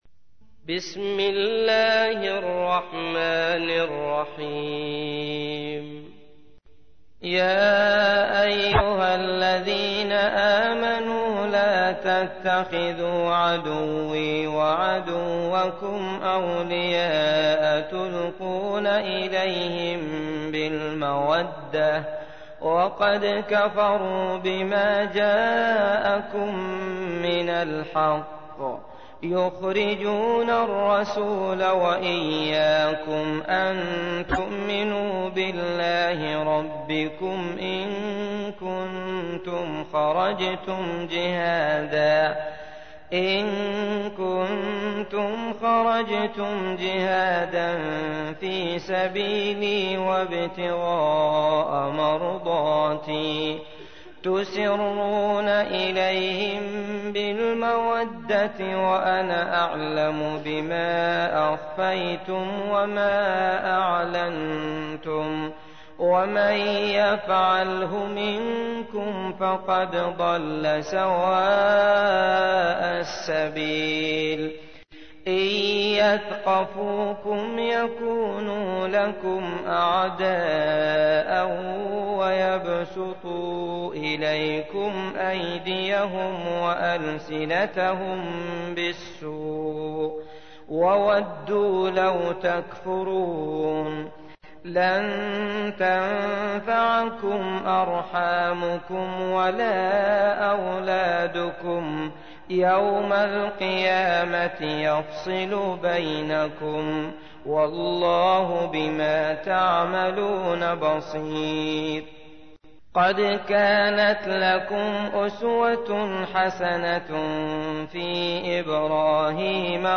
تحميل : 60. سورة الممتحنة / القارئ عبد الله المطرود / القرآن الكريم / موقع يا حسين